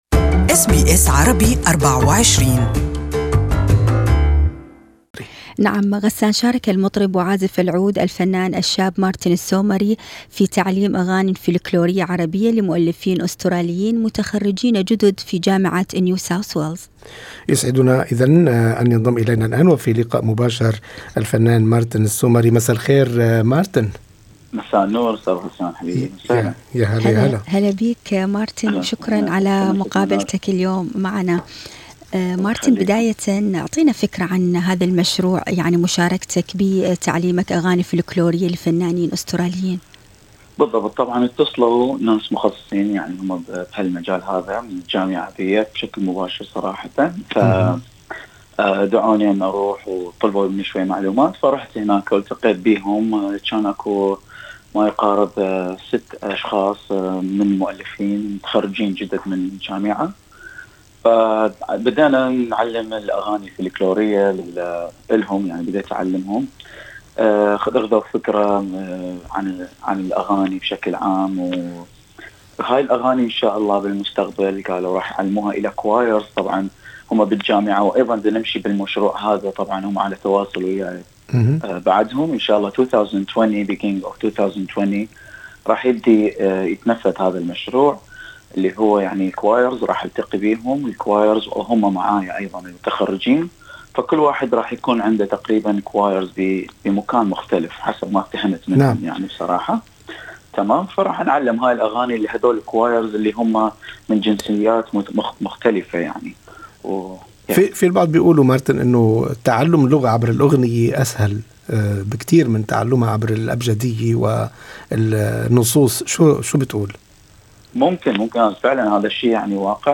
This interview is only available in Arabic